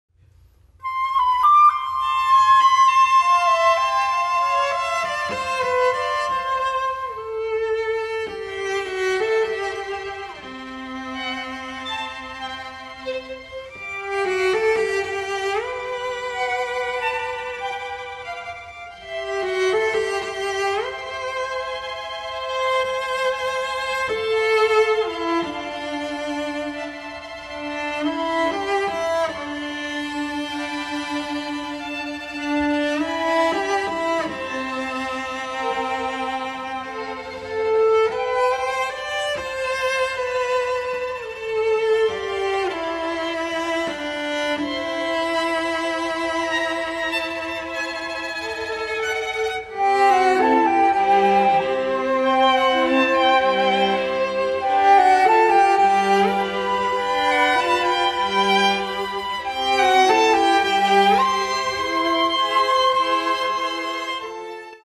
FLUTE QUARTET
(Flute, Violin, Viola and Cello)
(Two Violins, Viola and Cello)
MIDI